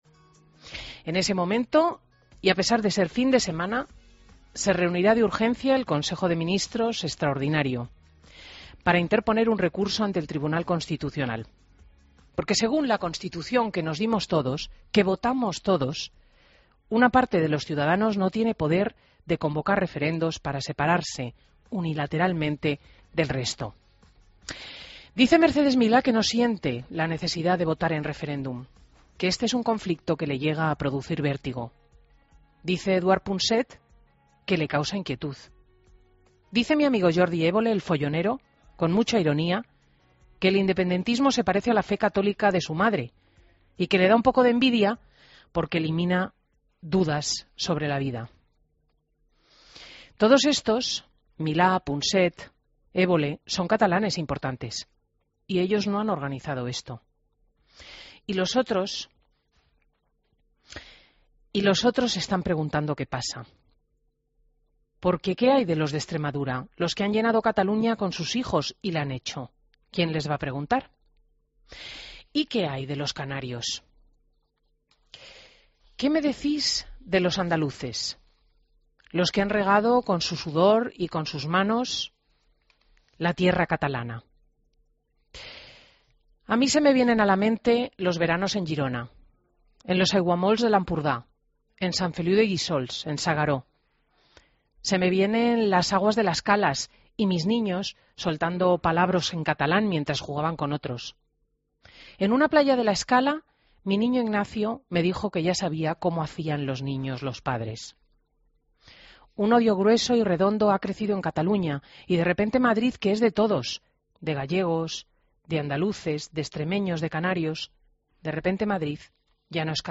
Entrevista a Enric Milló en Fin de Semana COPE